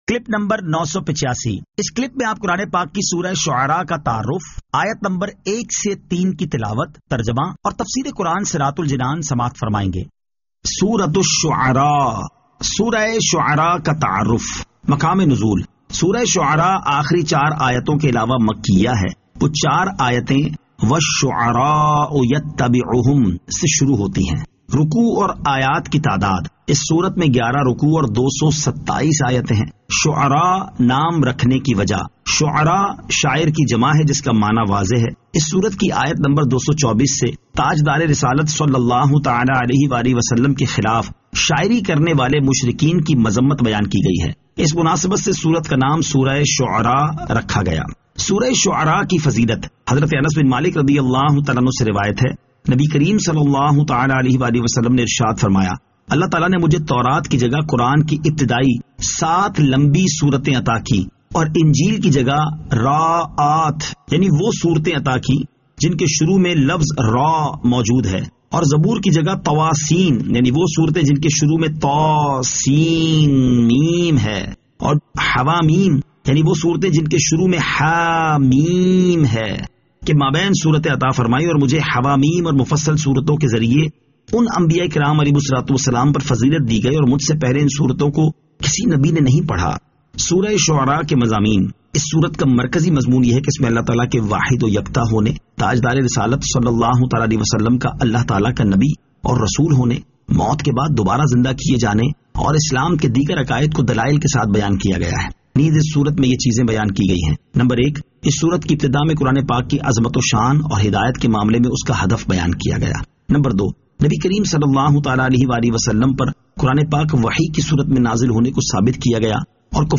Surah Ash-Shu'ara 01 To 03 Tilawat , Tarjama , Tafseer